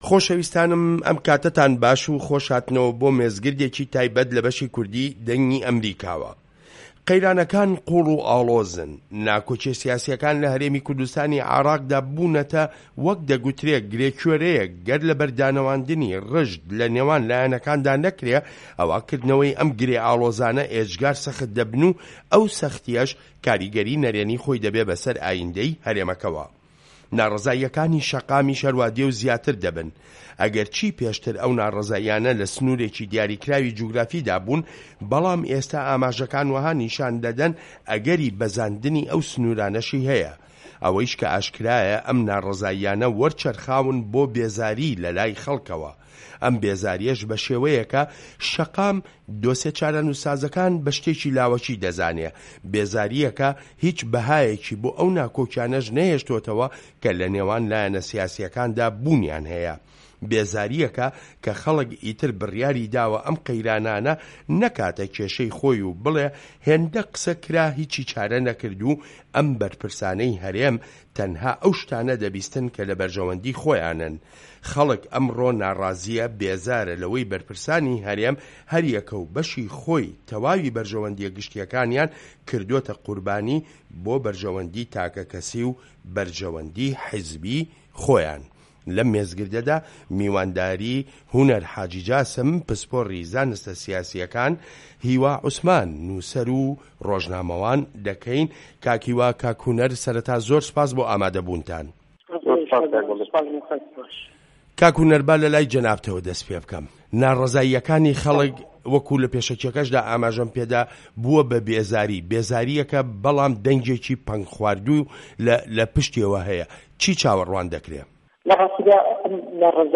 مێزگرد: قەیرانەکانی هەرێم و وەرچەرخانی ناڕەزاییەکانی خەڵک بۆ بێزاری